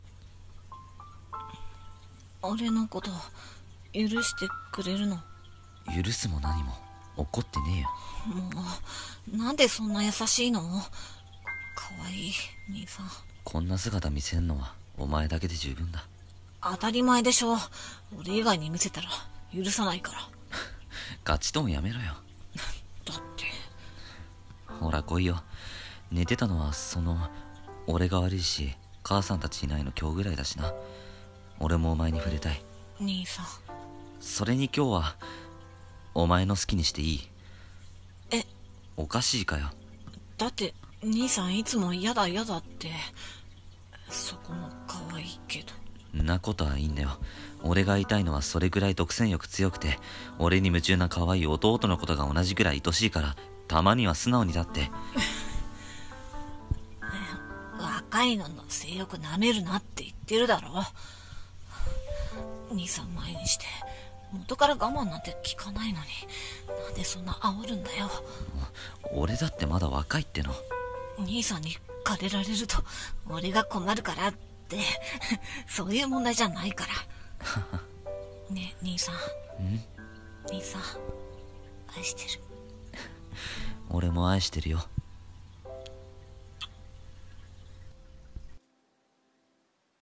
BL声劇